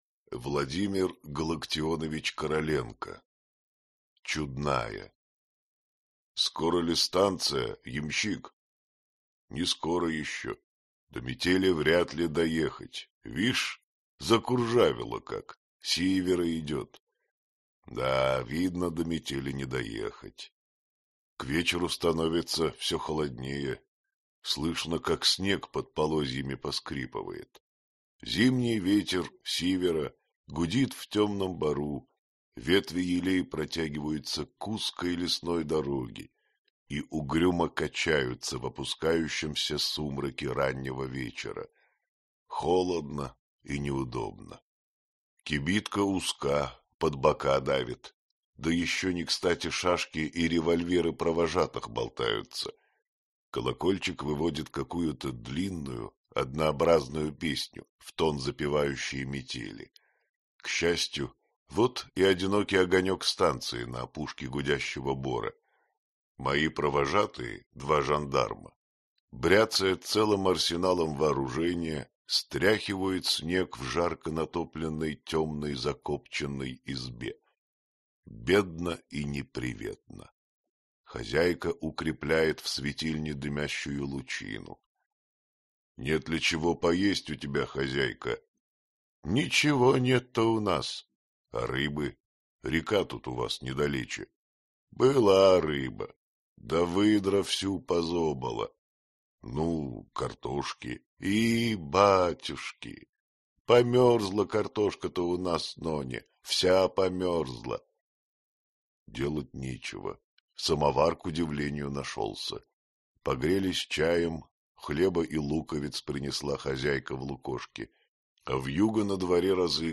Аудиокнига Чудная. Сказки и рассказы | Библиотека аудиокниг